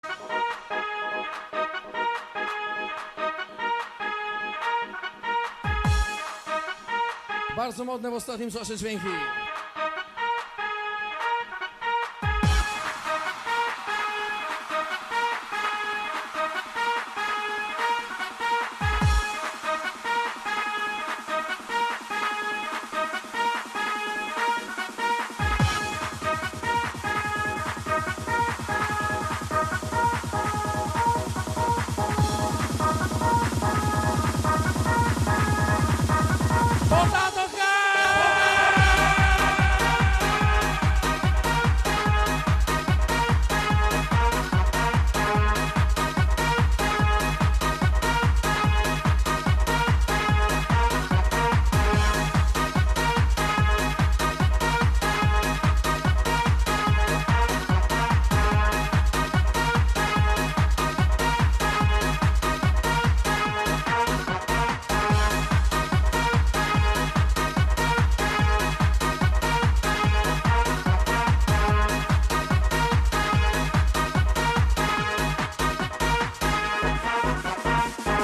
Stare techno, co to za nuta?